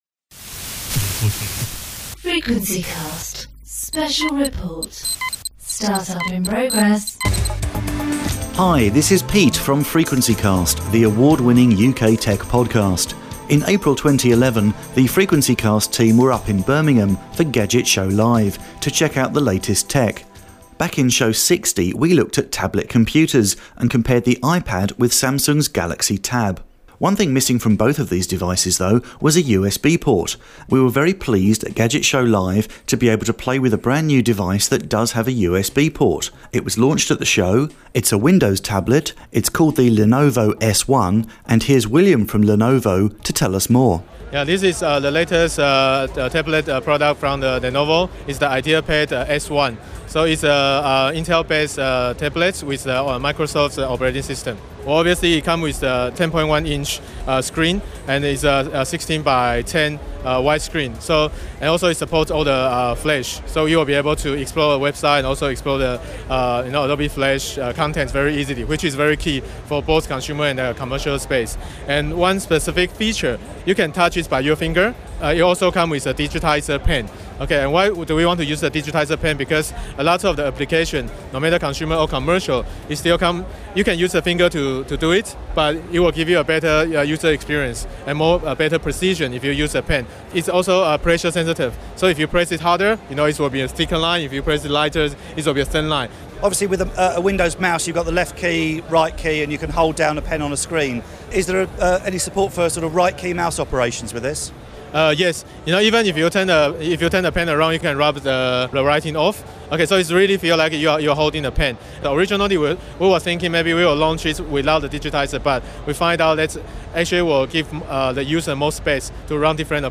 Lenovo announced their new Windows Tablet device, the Lenovo S1, at Gadget Show Live in 2011. Here, we interview one of the Lenovo team, and take a first look at what this new tablet has to offer